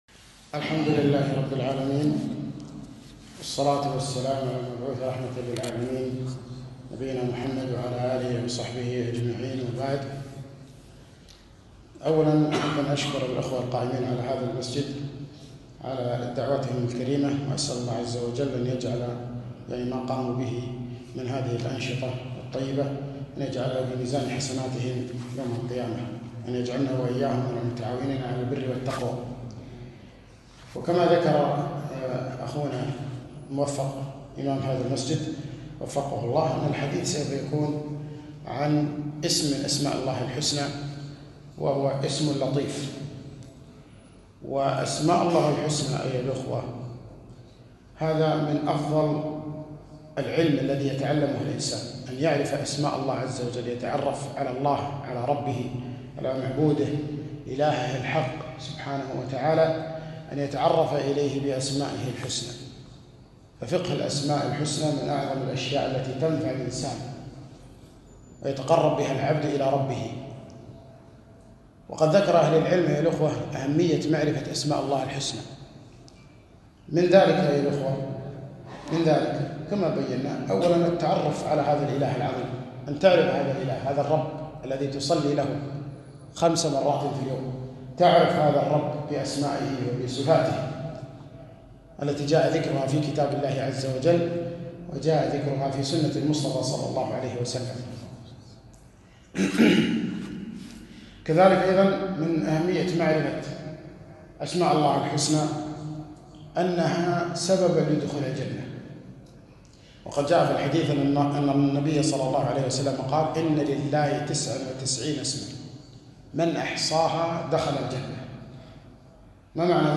محاضرة - اسم الله (اللطيف)